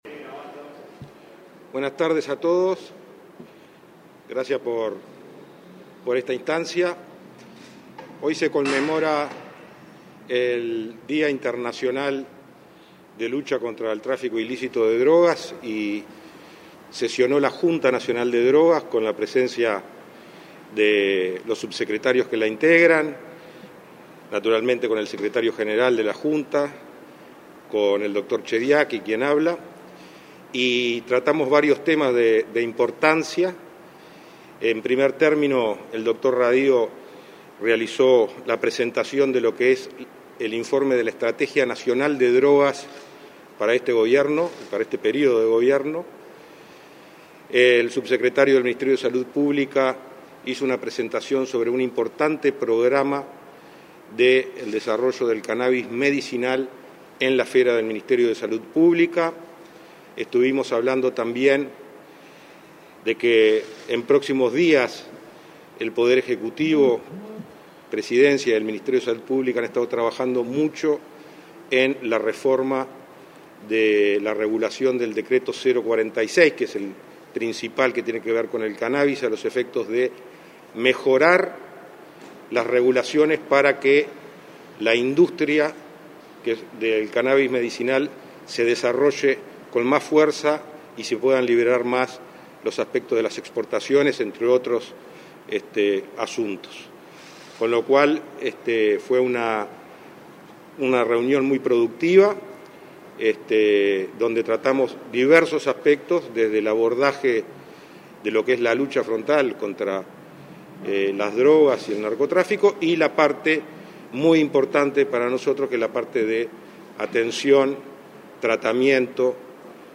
Conferencia de prensa del prosecretario Rodrigo Ferrés, el subsecretario del MSP, José Luis Sadjian y del secretario de la JDN, Daniel Radio
Conferencia de prensa del prosecretario de Presidencia y presidente de la Junta Nacional de Drogas (JND, Rodrigo Ferres, del secretario de la JND, Daniel Radio, y del subsecretario del Ministerio de Salud Pública, José Luis Sadjian, luego de la reunión de la JND que se realizó este viernes 25 en la Torre Ejecutiva.